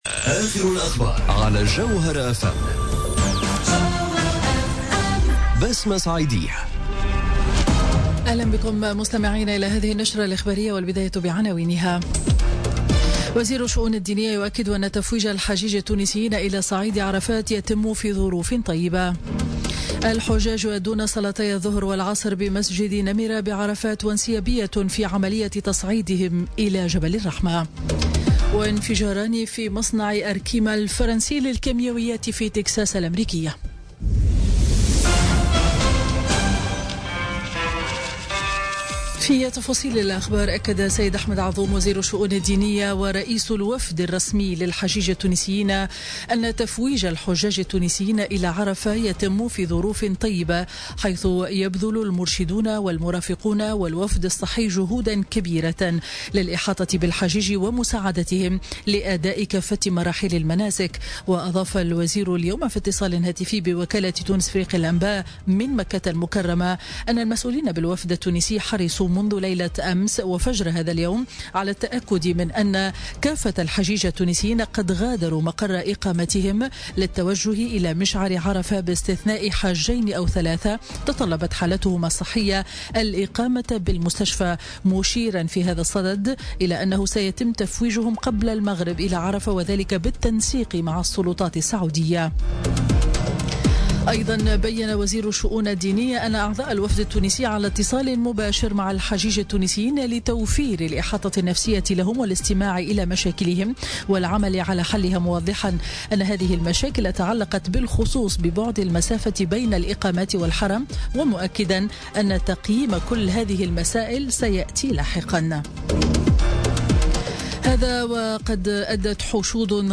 نشرة أخبار منتصف النهار ليوم الخميس 31 أوت 2017